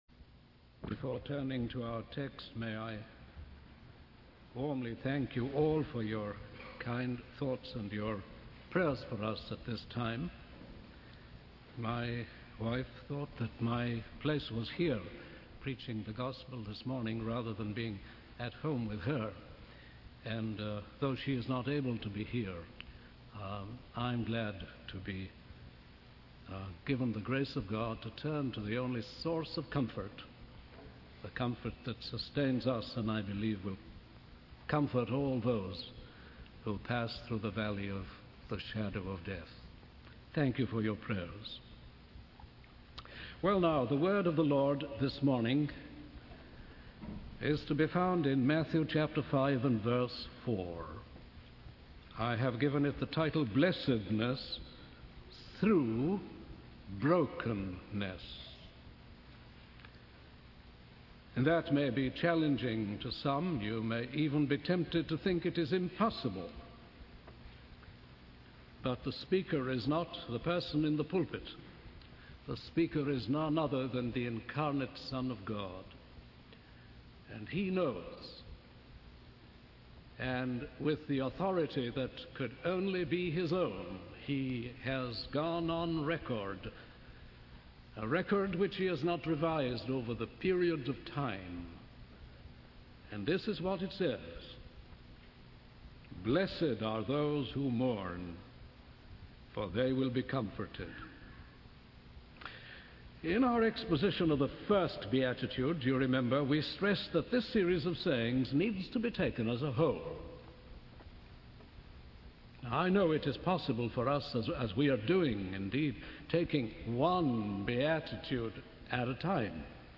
In this sermon, the speaker begins by expressing gratitude for the prayers and support received during a difficult time. The sermon is based on Matthew chapter 5, verse 4, and is titled 'Blessedness through Brokenness.' The speaker emphasizes that the words spoken by Jesus in the Beatitudes are not just random statements, but a complete picture of the Christian life.